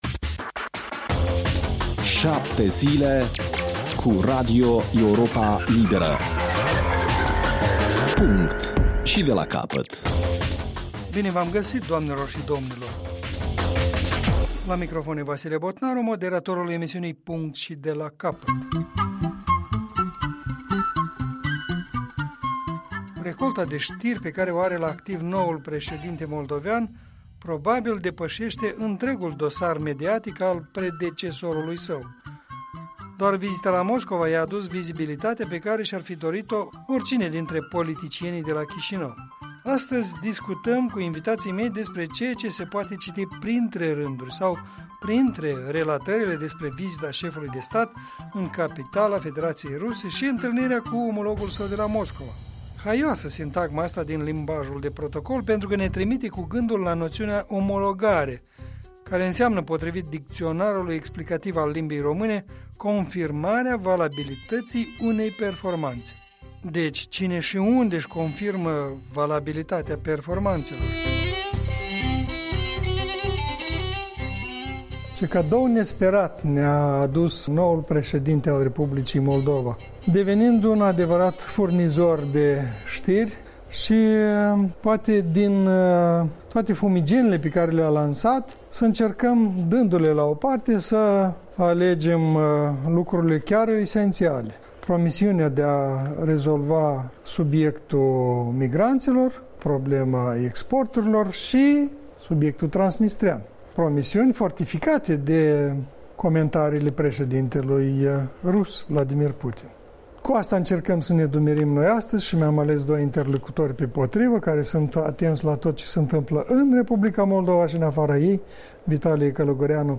Punct și de la capăt - o emisiune moderată